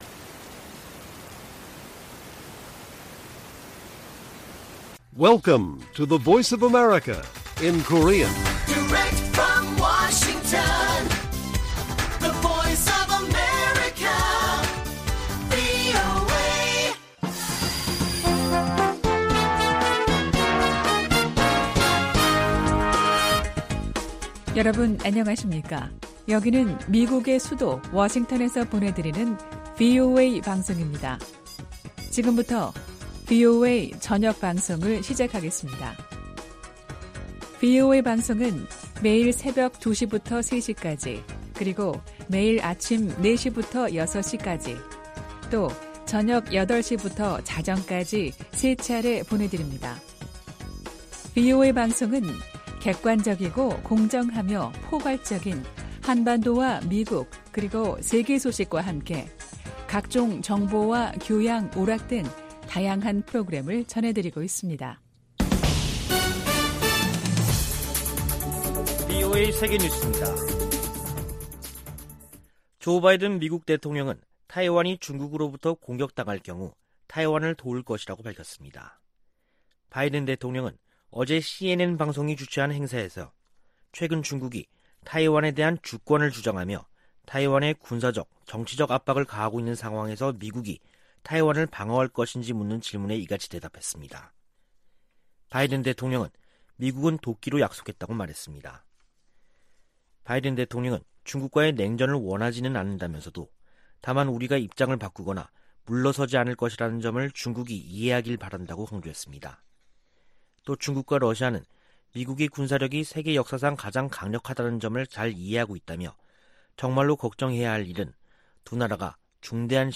VOA 한국어 간판 뉴스 프로그램 '뉴스 투데이', 2021년 10월 22일 1부 방송입니다. 북한이 가능한 모든 영역에서 핵 개발에 전력을 다하고 있다고 국제원자력기구(IAEA) 사무총장이 지적했습니다. 미국은 제재 사용에 관해 동맹· 파트너 국가들과의 협의와 협력을 중시할 것이라고 미 재무부 부장관이 밝혔습니다. 북한이 여전히 대량살상무기 기술 이전 역할을 하고 있다고 낸시 펠로시 미 하원의장이 지적했습니다.